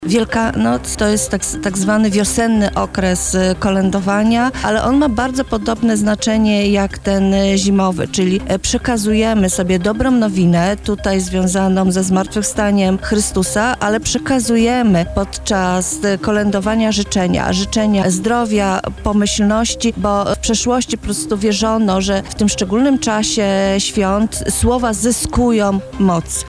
[PORANNA ROZMOWA] Jak świętuje się Wielkanoc na Lubelszczyźnie?